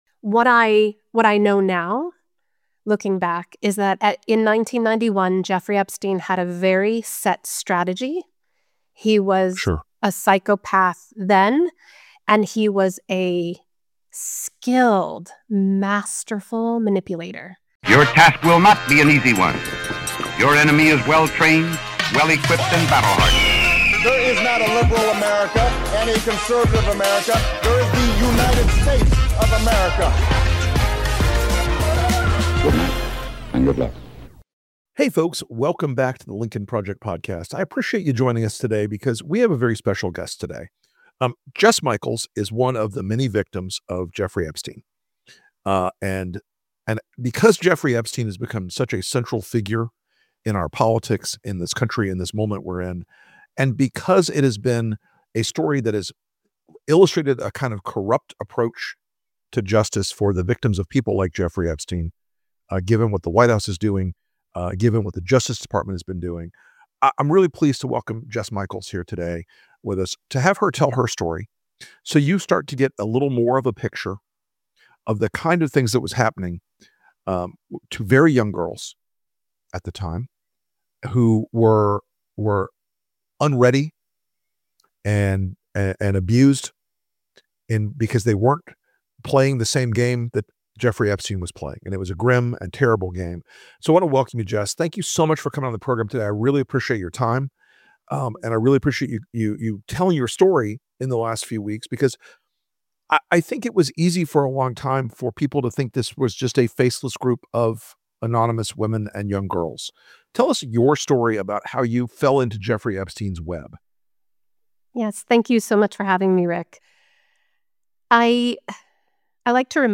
In a country where the rich and powerful still shield predators, this conversation is a raw reminder of what it takes to survive, to fight, and to demand truth when the system was manipulated to cover it up.